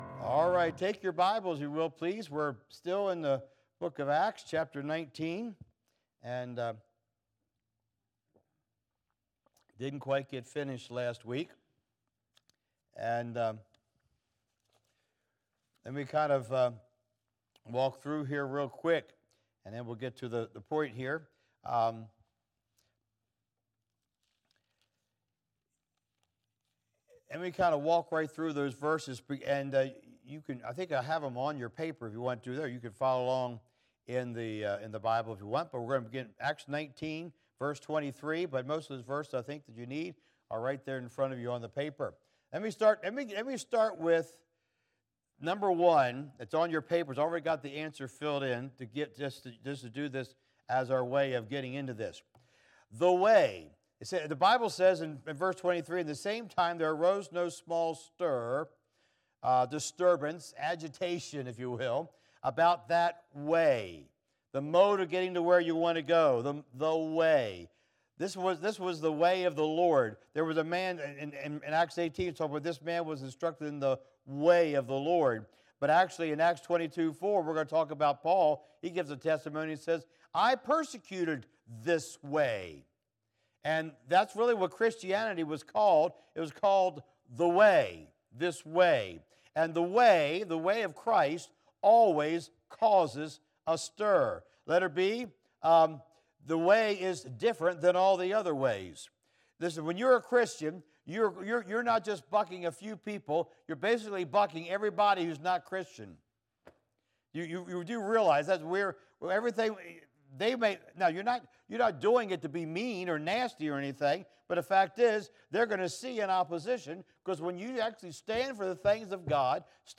I Will Build My Church Passage: Acts 19:21-41 Service Type: Wednesday Evening « Accommodating Satan God’s Controversy